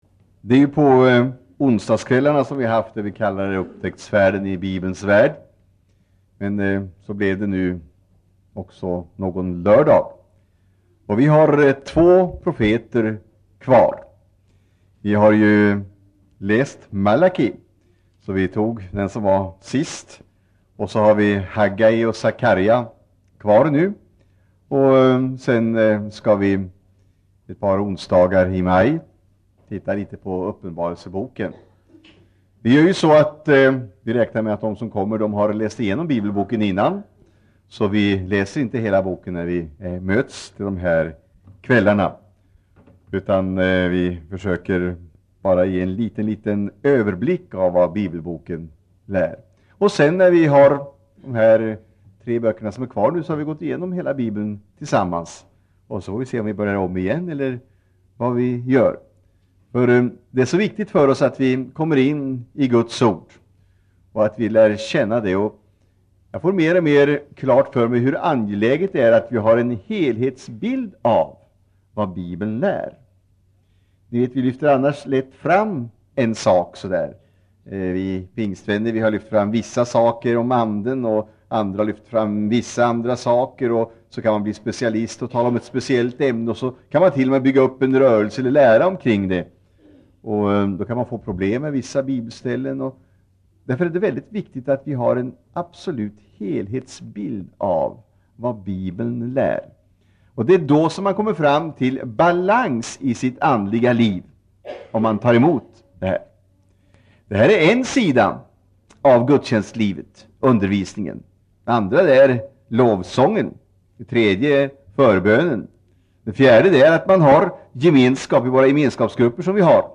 Inspelad i Citykyrkan, Stockholm 1984-05-03.